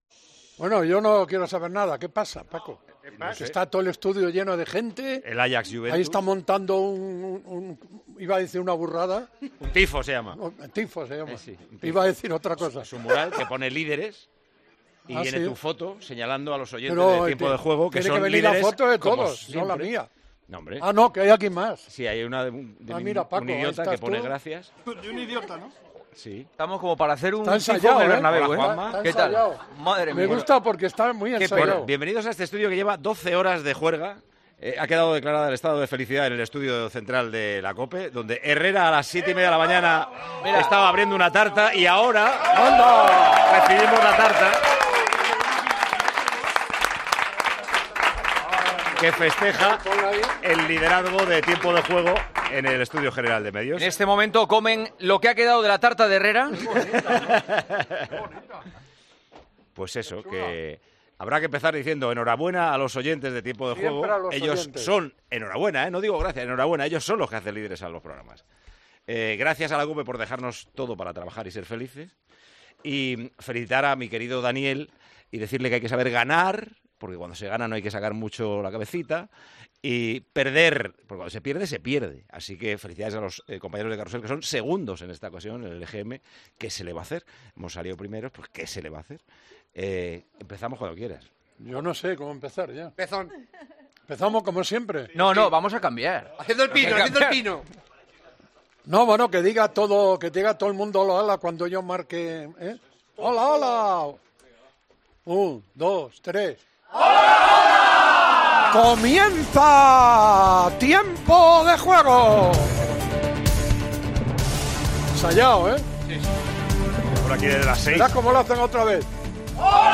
Paco González y Pepe Domingo Castaño celebran en el comienzo del programa el liderato en el EGM.